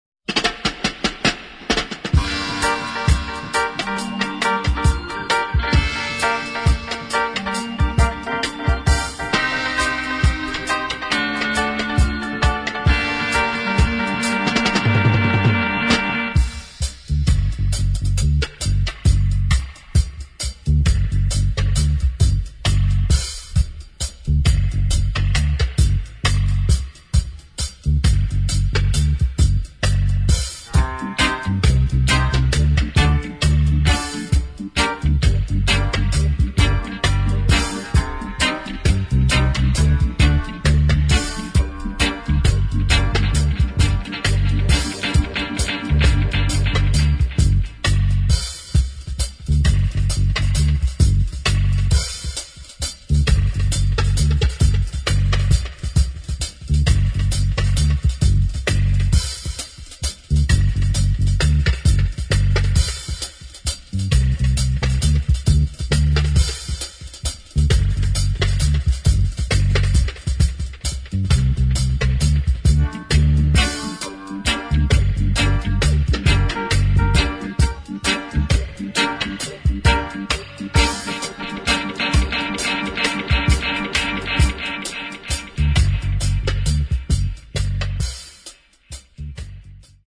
[ REGGAE | DUB ]